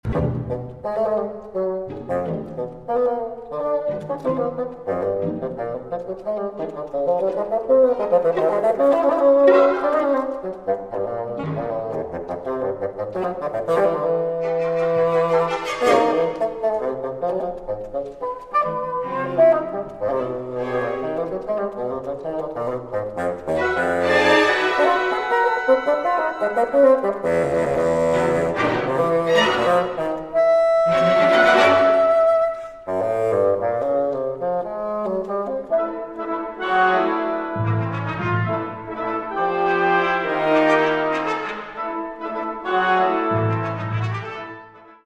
First Recordings made in the Presence of the Composer